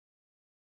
MALBELLWAV.wav